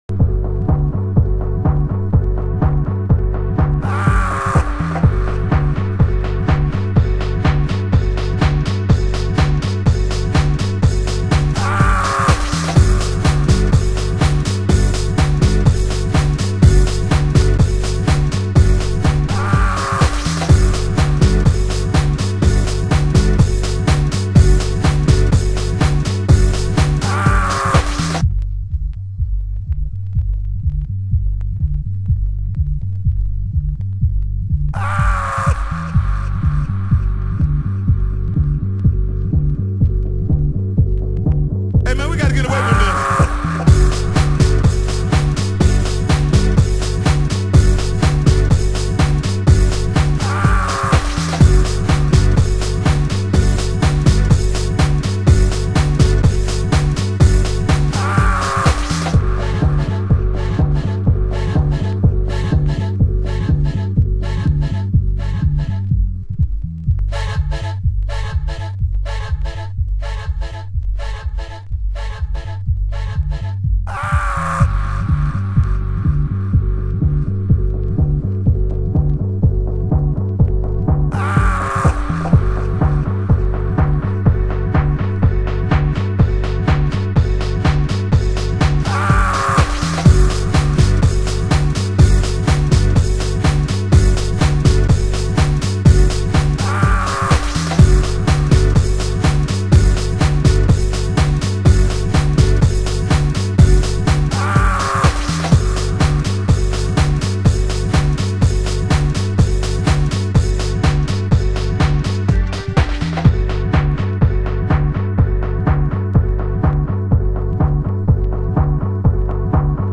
Tribal Mix